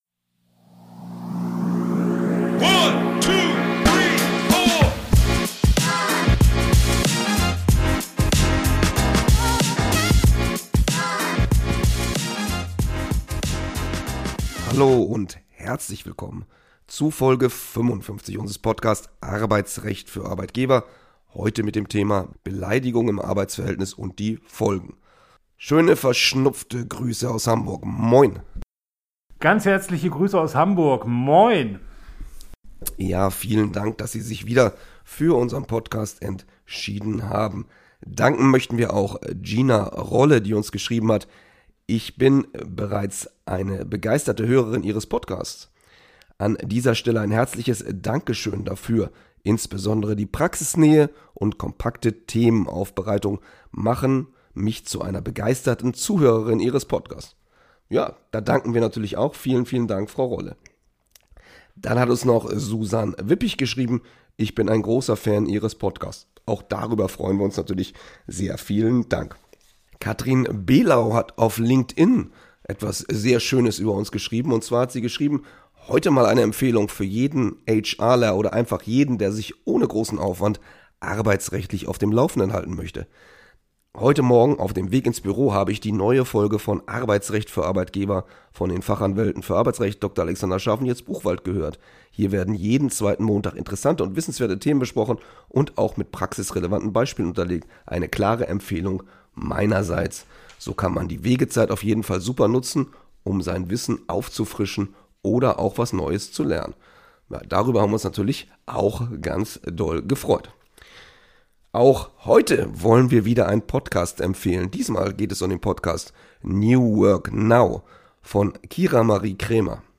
Für Arbeitgeber - neue Urteile, neue Gesetze, Praxistipps und grundlegende Informationen rund um alle arbeitsrechtlichen Fragestellungen. Zwei Fachanwälte für Arbeitsrecht